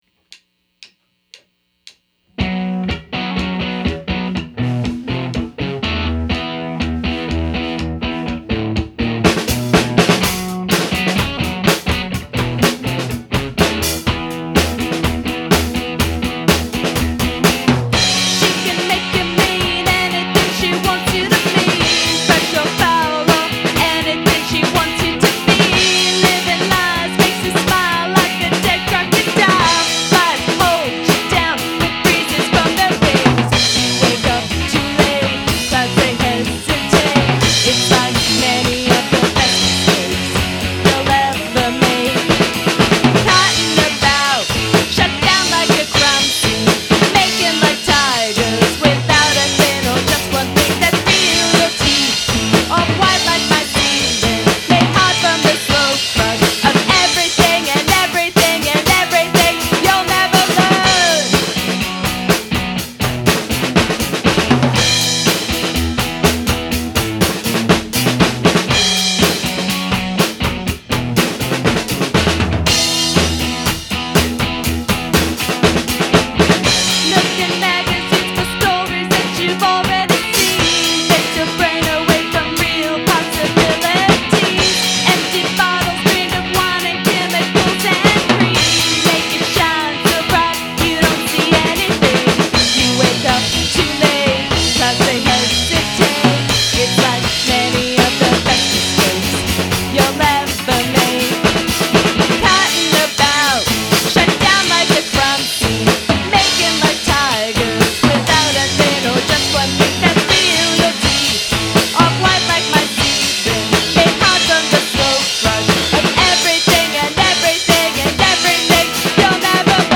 3-2-2010 Recording Session